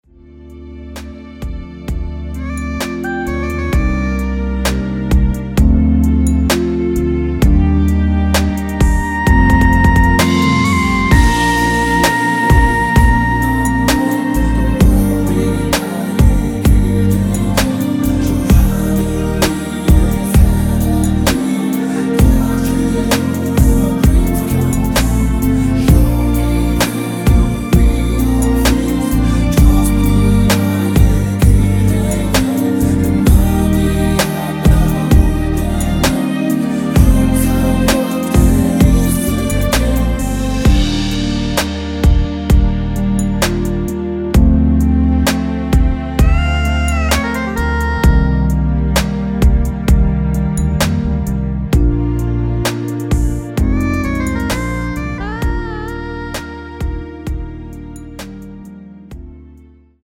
원키에서(-1)내린 코러스 포함된 MR 입니다.
Bb
앞부분30초, 뒷부분30초씩 편집해서 올려 드리고 있습니다.
중간에 음이 끈어지고 다시 나오는 이유는